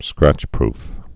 (scrăchprf)